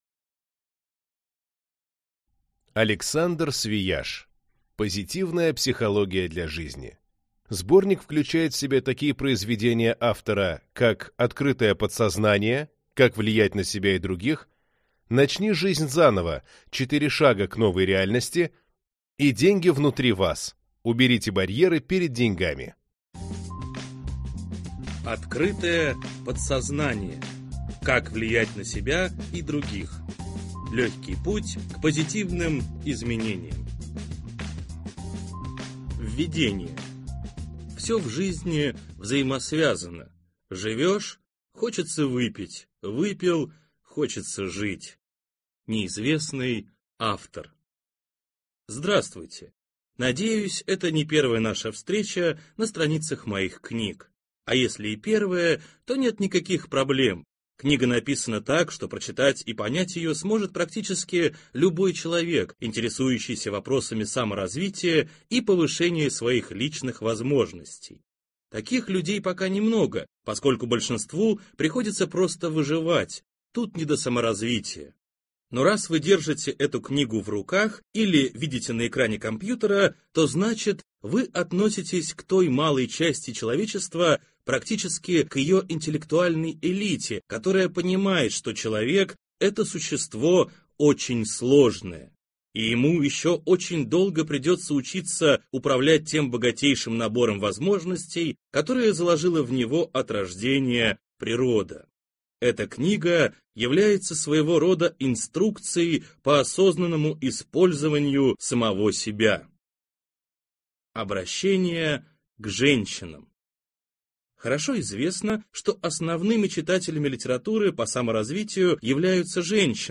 Аудиокнига Позитивная психология для жизни | Библиотека аудиокниг